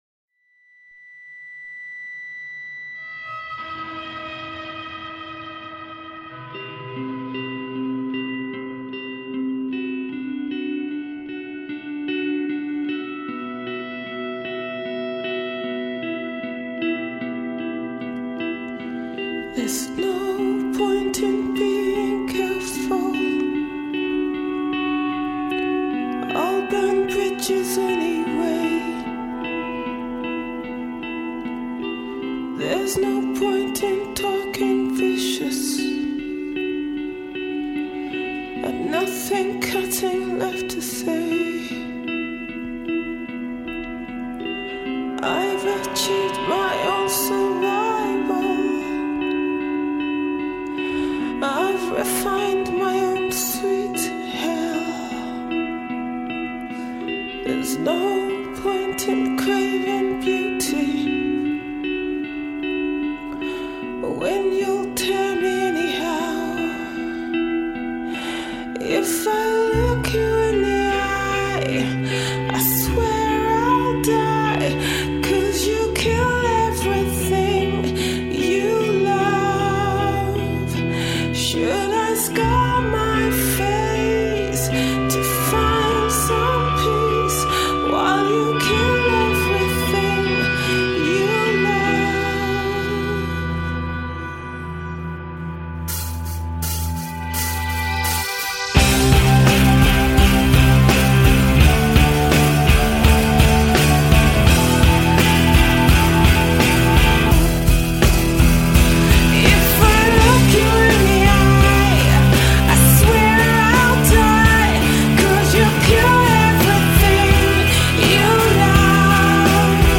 Жанр: HIP-HOP ROCK